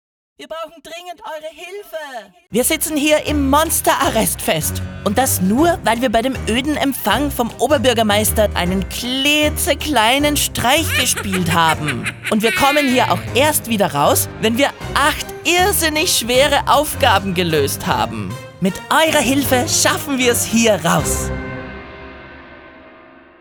Ein Haufen von frechen kleinen Monstern bittet die Kids persönlich und sehr lustig um Hilfe.
Zeit, die Audio-Datei_2 abzuspielen, in der sich die frechen Monster laut und durcheinander sehr begeistert bei den cleveren Kids bedanken!